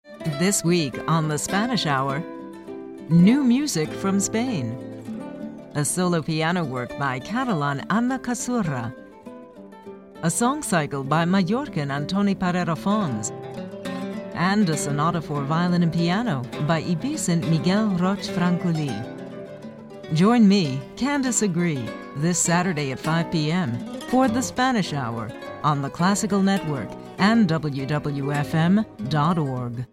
The classical station WWFM broadcast the latest Foundation for Iberian Music’s Composer’s Commissions on the program The Spanish Hour